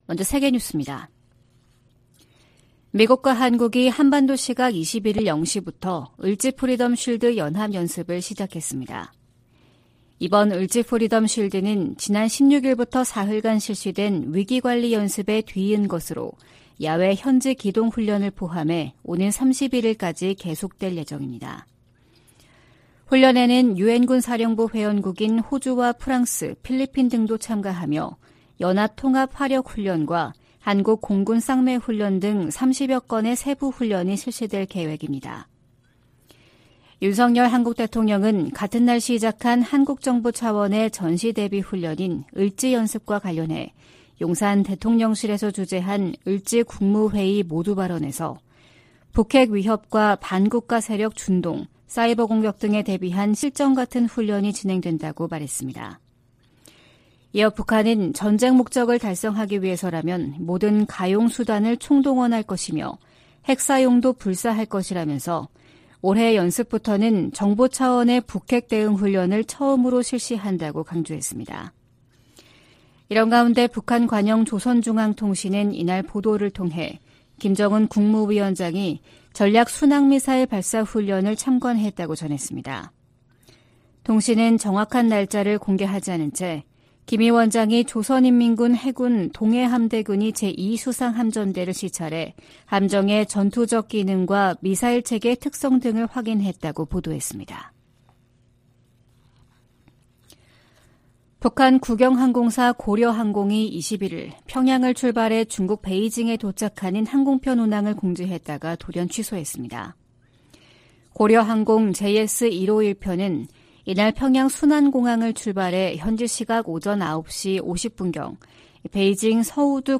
VOA 한국어 '출발 뉴스 쇼', 2023년 8월 22일 방송입니다. 미국과 한국, 일본 정상들은 18일 채택한 캠프 데이비드 정신에서 3국 정상 회의를 연 1회 이상 개최하기로 합의했습니다. 윤석열 한국 대통령은 북한의 도발 위협이 커질수록 미한일 안보 협력은 견고해질 것이라고 밝혔습니다. 조 바이든 미국 대통령은 우크라이나에서와 같은 사태가 아시아에도 벌어질 수 있다고 경고하며 안보 협력 중요성을 강조했습니다.